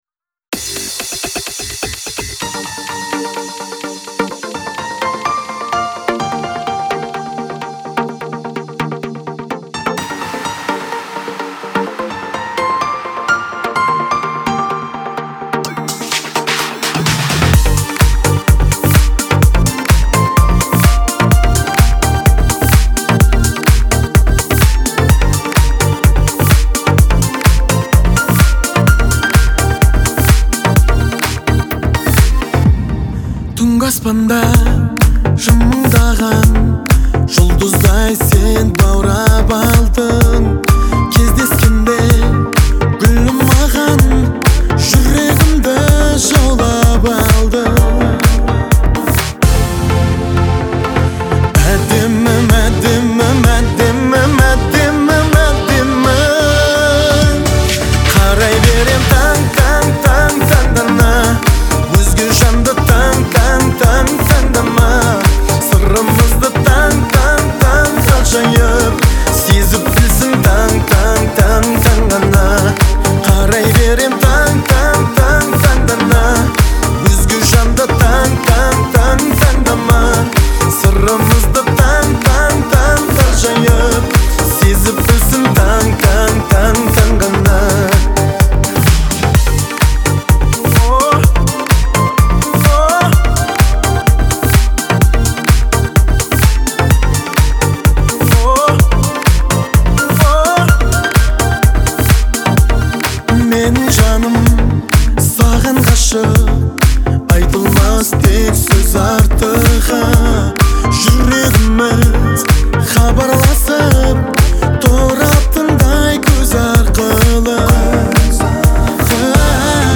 это романтическая песня в жанре казахской поп-музыки